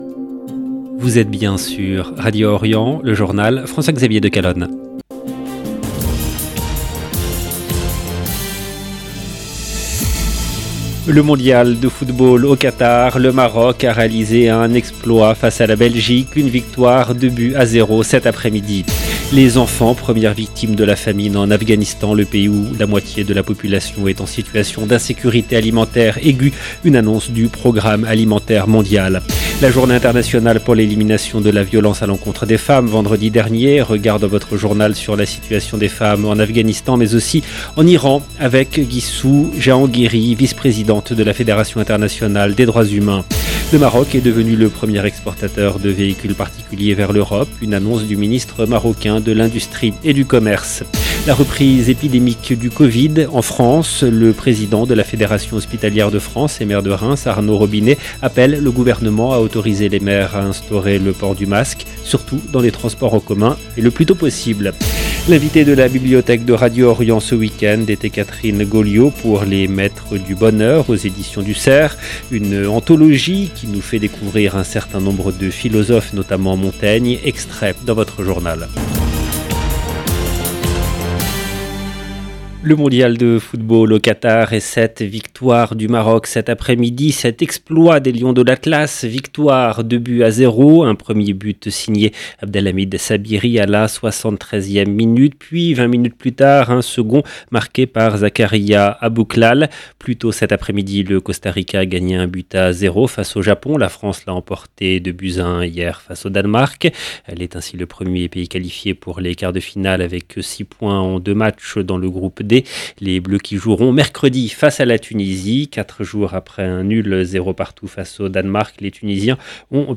EDITION DU JOURNAL DU SOIR EN LANGUE FRANCAISE DU 27/11/2022